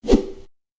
Melee Weapon Air Swing 5.wav